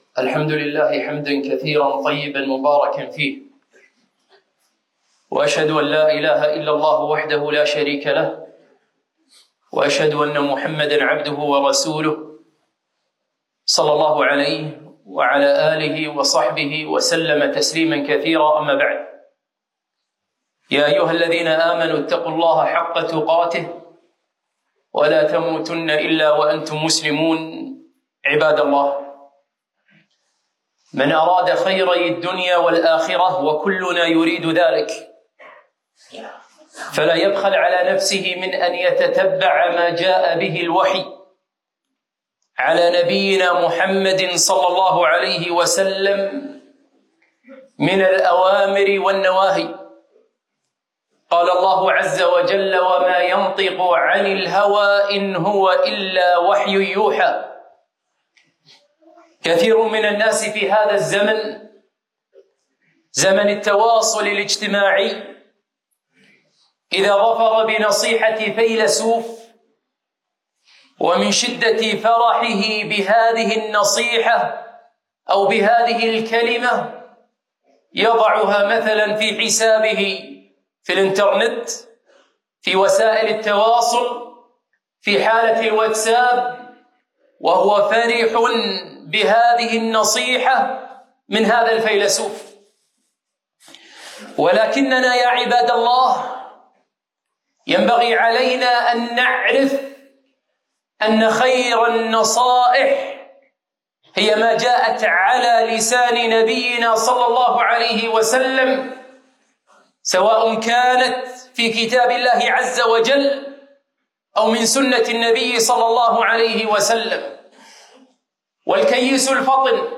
خطبة - نصائح نبوية
خطبة الجمعة ألقيت في فرنسا 3-5-2024